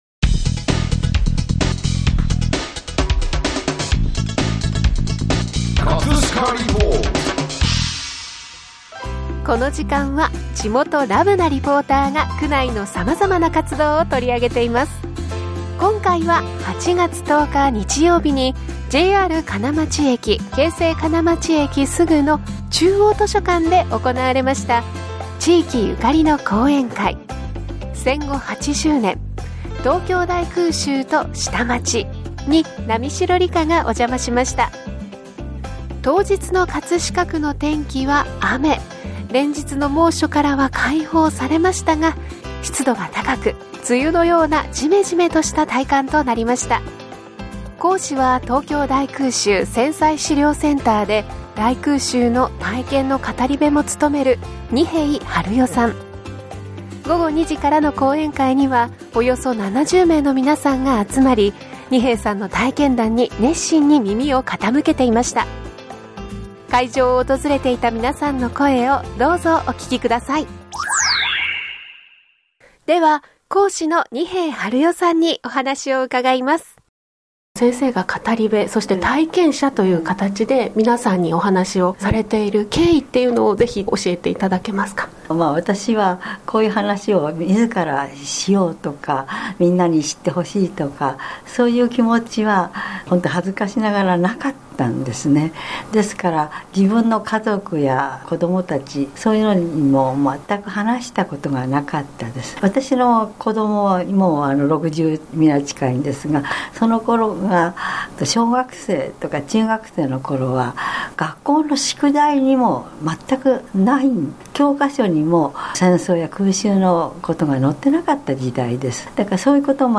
【葛飾リポート】 葛飾リポートでは、区内の様々な活動を取り上げています。